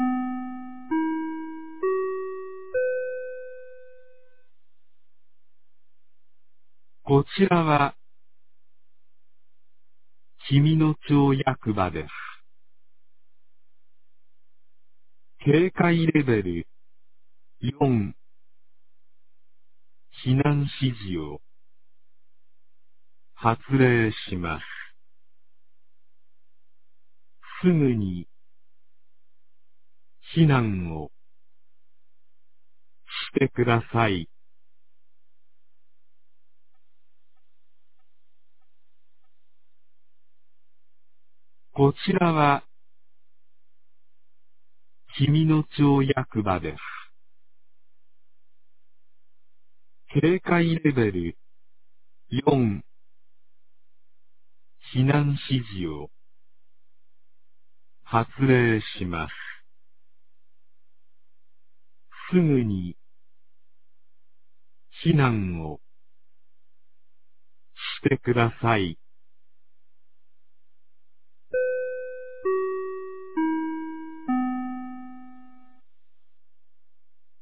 2023年06月02日 12時13分に、紀美野町より全地区へ放送がありました。
放送音声